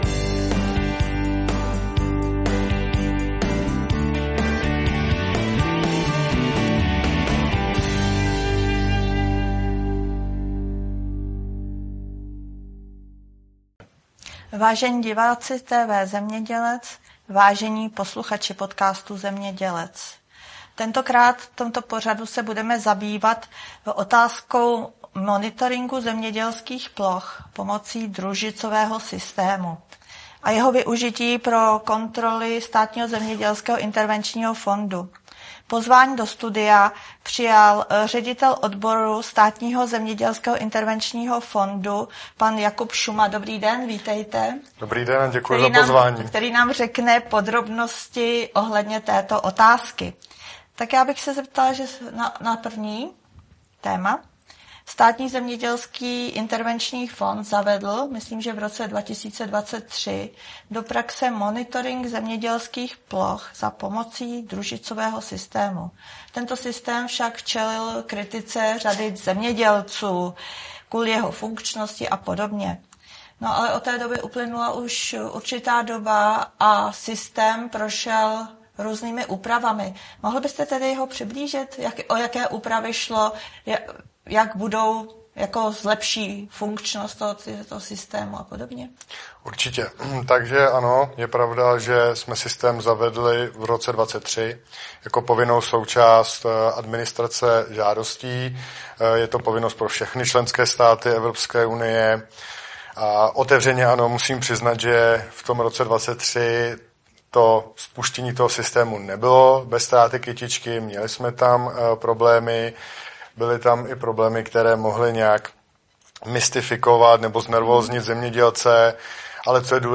Úbytek účinných látek v ochraně rostlin: dopady na potravinovou soběstačnost České republiky a bilanci zahraničního obchodu 25.listopadu 2025, Praha
Téma: Rostlinná výroba , Výzkumný ústav a věda Partner: BASF spol. s r.o. Pořad: Odborná diskuse , Seminář Audio záznam: Poslechnout si